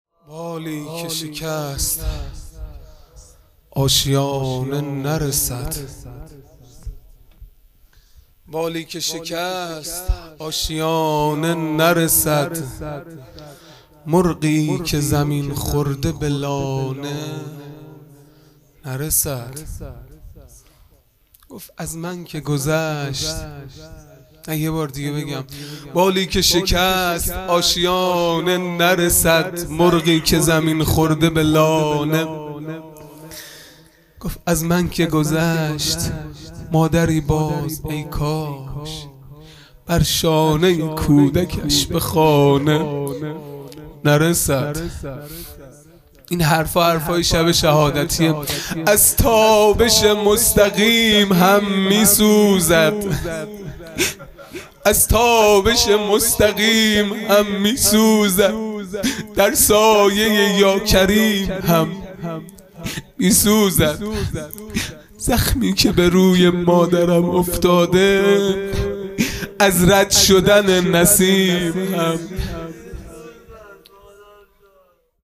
خیمه گاه - هیئت بچه های فاطمه (س) - مرثیه | بالی که شکست، آشیانه نرسد
فاطمیه دوم(شب دوم)